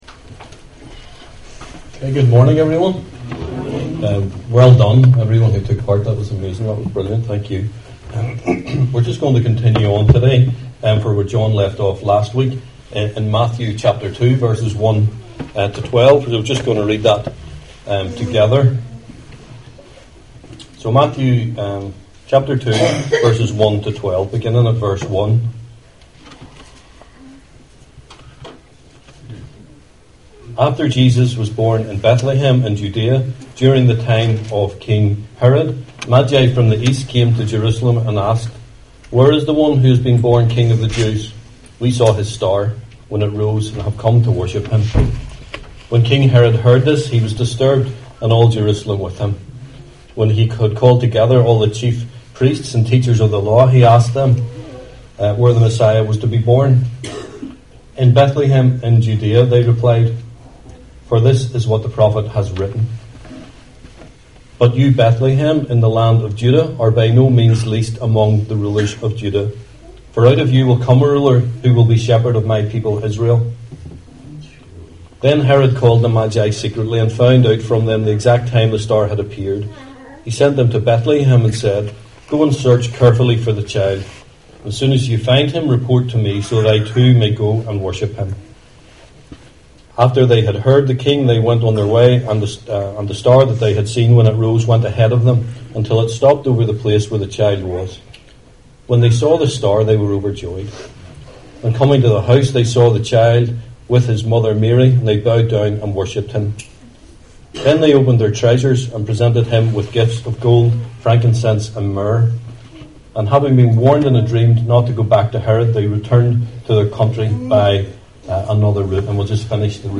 Service Type: 11am